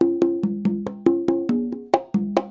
Free MP3 percussions sounds 3